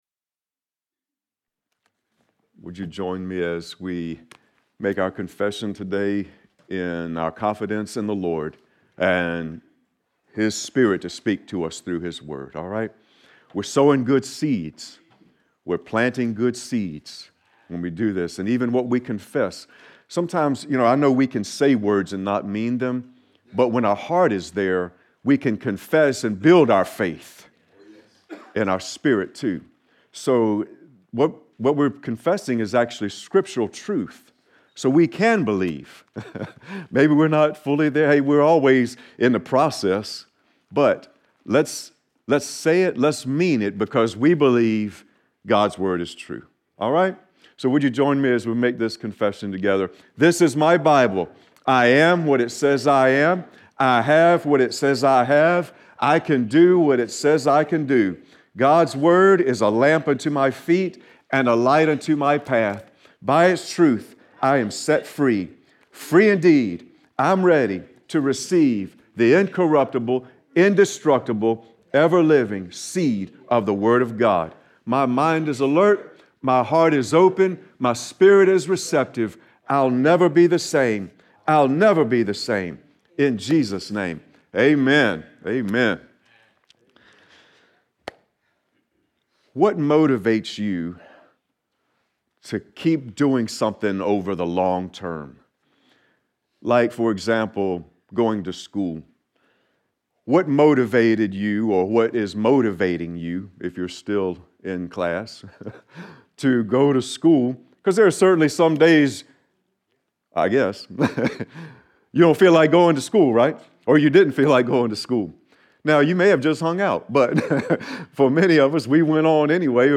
Sermon-Christs-Love-Urges-Us-On.mp3